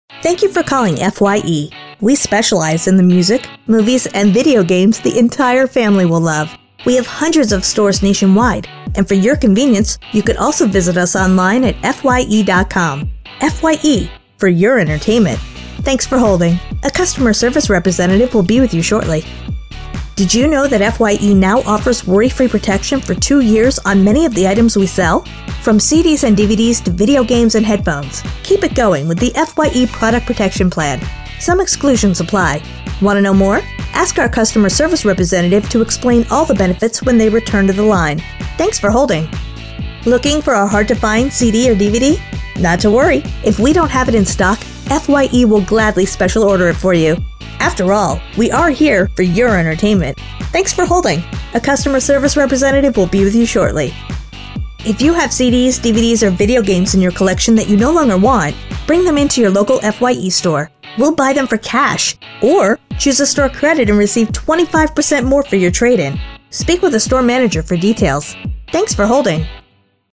IVR/On Hold
American English (Neutral), American English (Southern)
FYE On Hold Messaging Demo_0.mp3